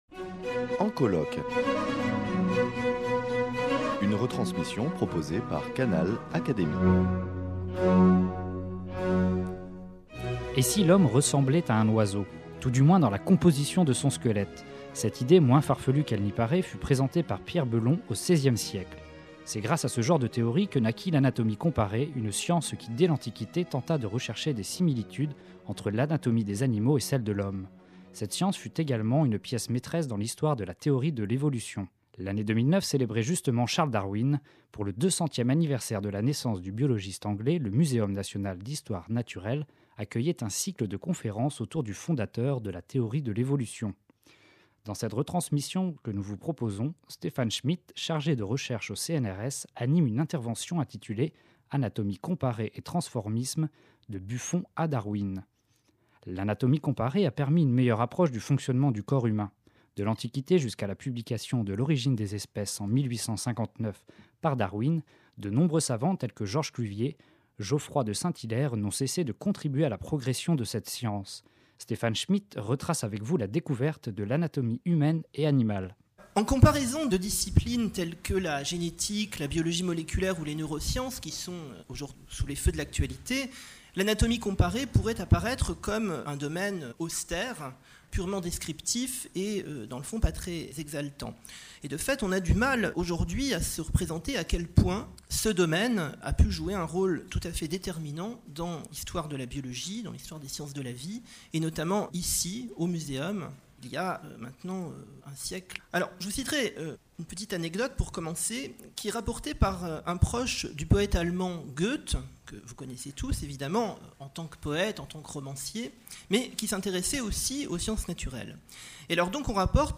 » La Grande Galerie de l’Evolution du Muséum national d’Histoire naturelle accueille depuis la rentrée 2009, des cycles de conférence autour de la nature et la science.